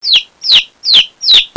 Chick
chick.wav